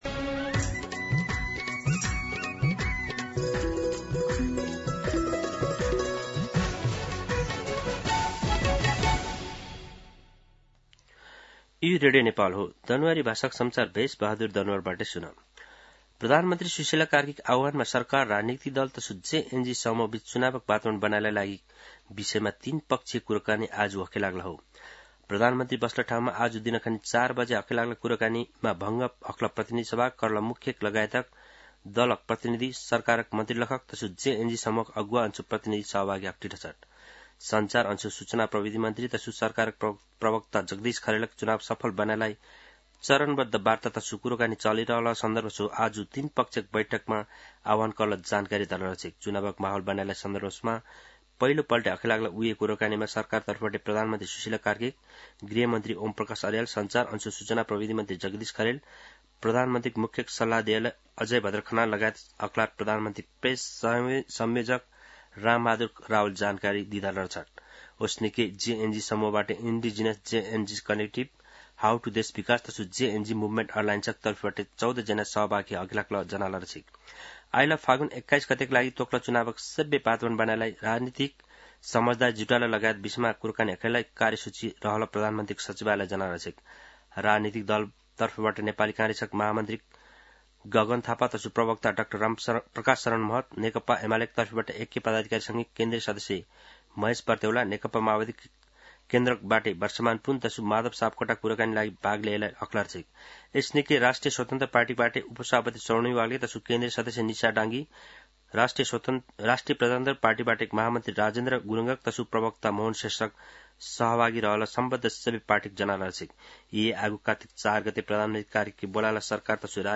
An online outlet of Nepal's national radio broadcaster
दनुवार भाषामा समाचार : १२ कार्तिक , २०८२
Danuwar-News-12.mp3